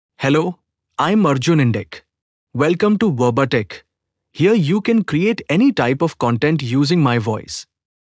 MaleEnglish (India)
Arjun IndicMale English AI voice
Arjun Indic is a male AI voice for English (India).
Voice sample
Listen to Arjun Indic's male English voice.
Arjun Indic delivers clear pronunciation with authentic India English intonation, making your content sound professionally produced.